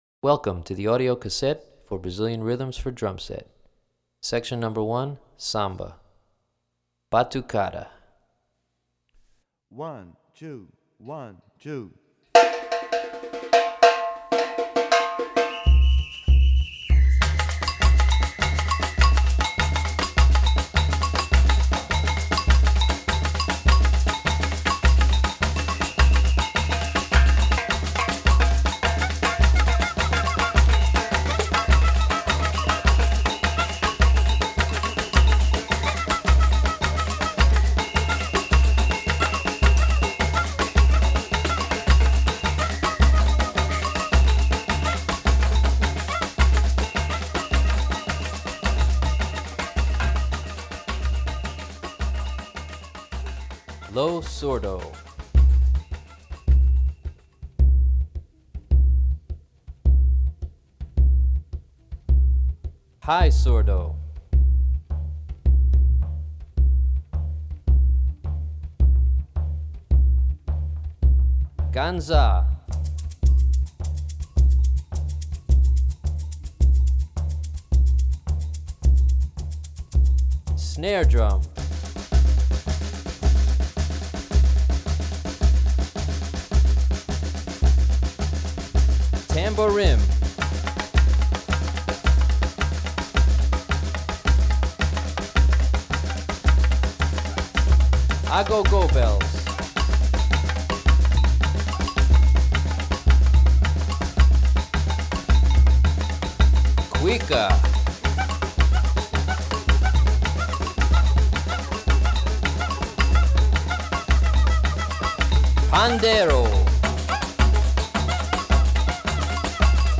01_Batucada_Drums.mp3